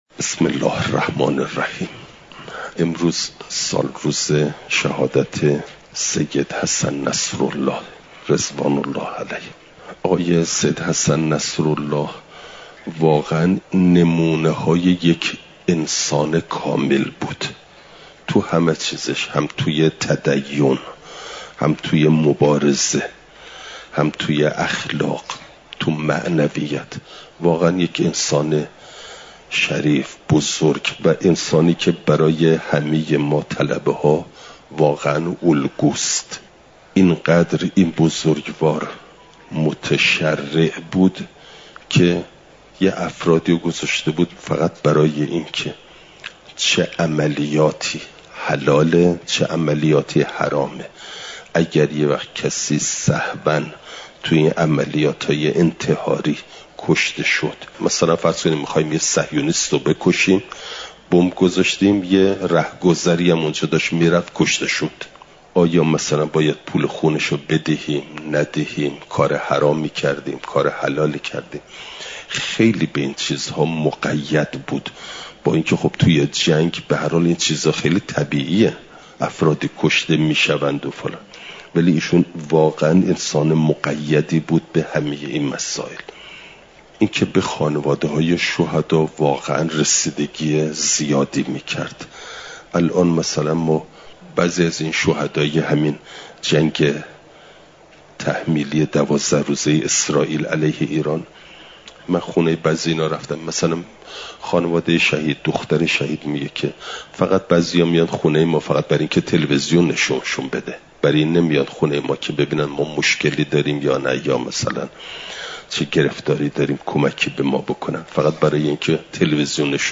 صبح امروز در درس خارج خود به مناسبت سالگرد شهادت سید حسن نصرالله، نکاتی مهمی درباره این شهید بزرگوار بیان نمود